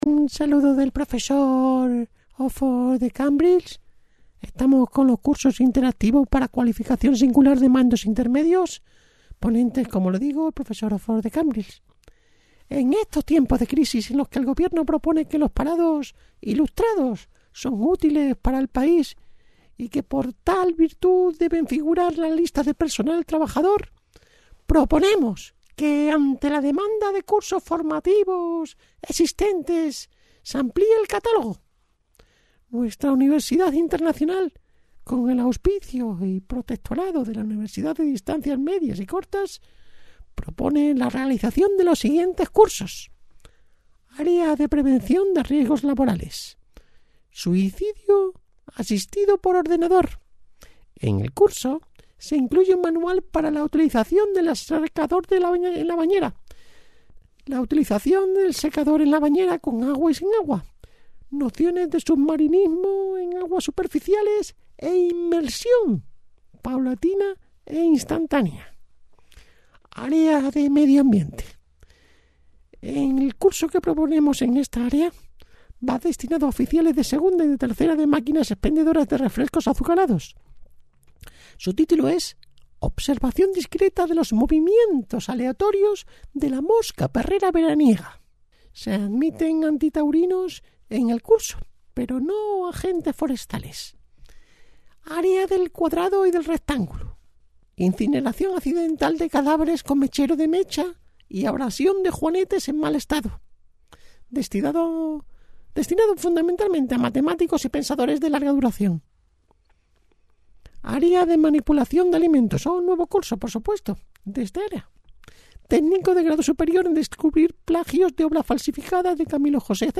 monologo_television_cursos_formativos_absurdos_locutortv.MP3